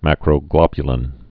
(măkrō-glŏbyə-lĭn)